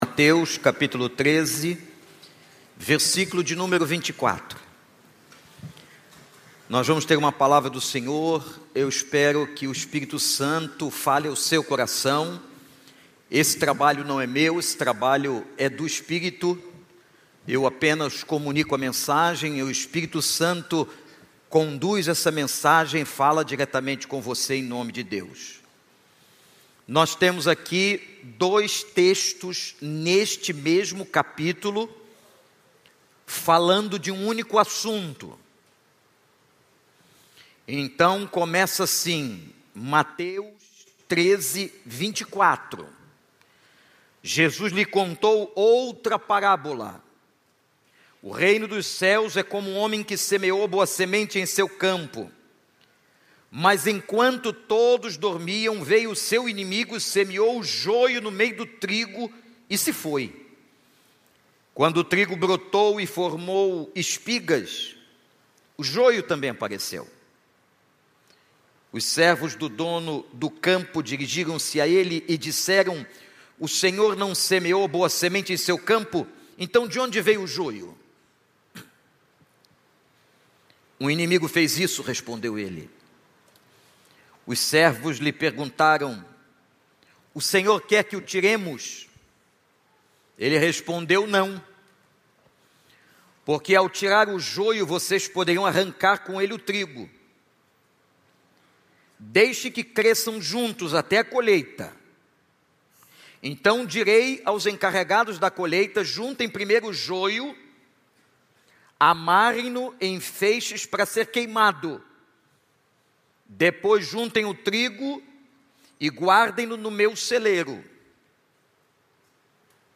Mensagem
na Igreja Batista do Recreio